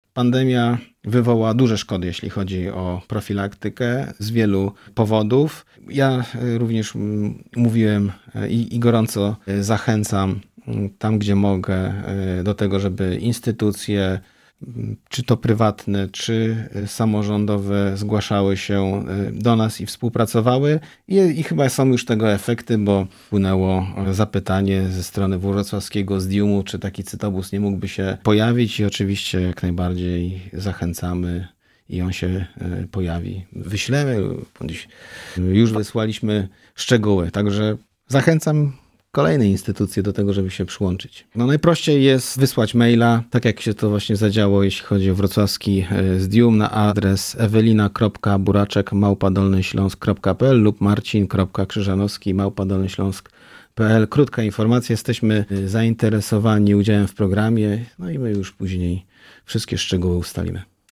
Mówi Marcin Krzyżanowski – Wicemarszałek Województwa Dolnośląskiego.